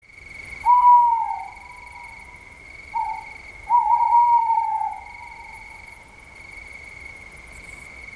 Eule klingelton kostenlos
Kategorien: Tierstimmen